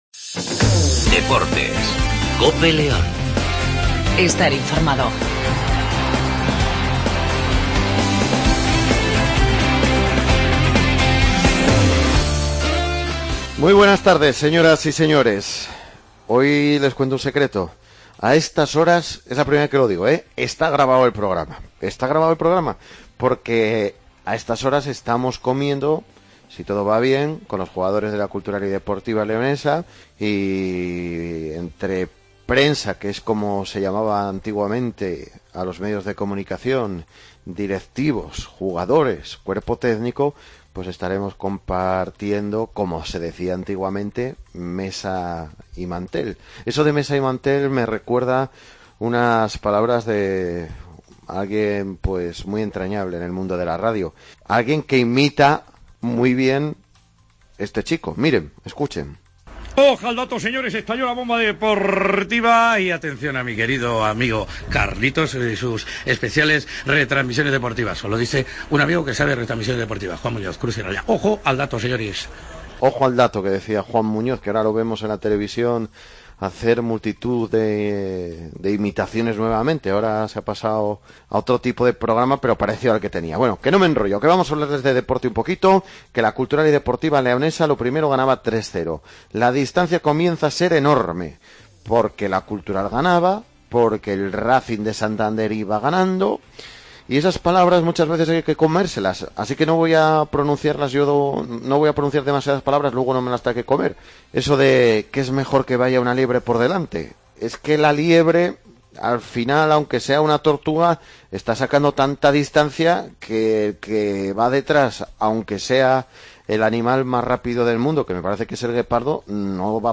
rafa guijosa ( Entrenador del " Abanca Ademar " )